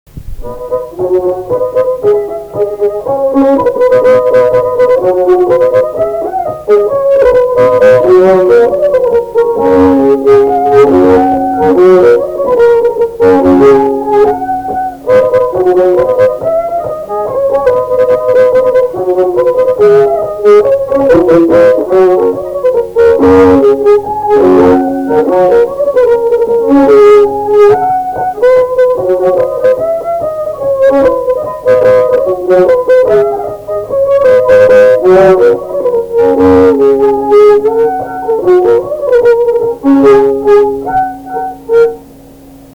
Polka
šokis